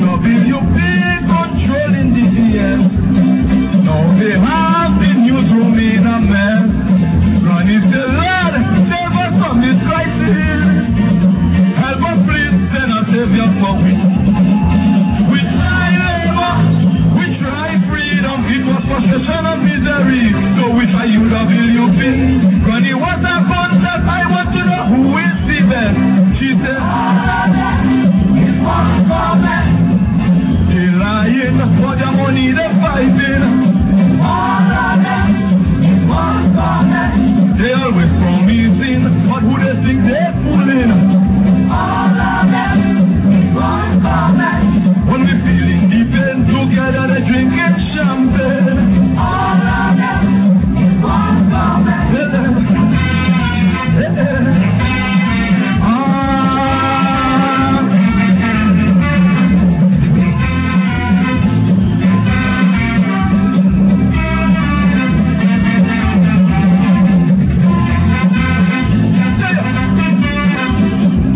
Click on these MEMORABLE CAISO SNIPETS(soon on DVD)